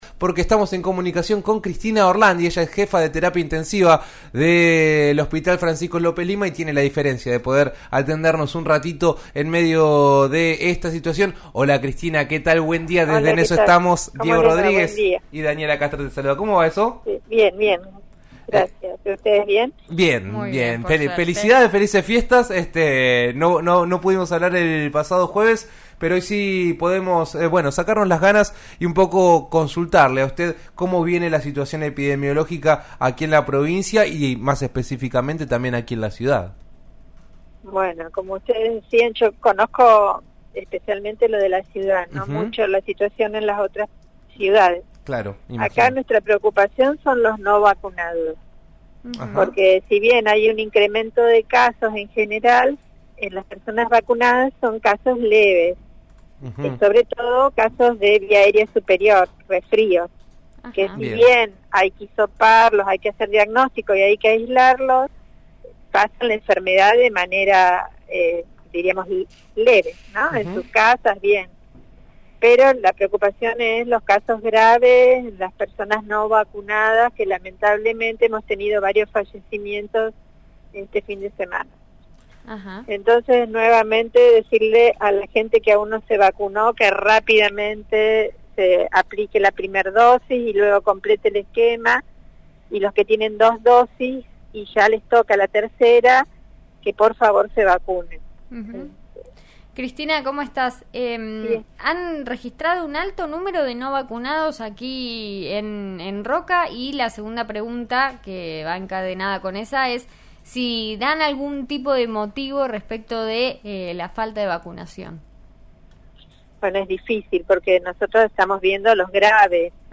al aire de 'En Eso Estamos' de RN RADIO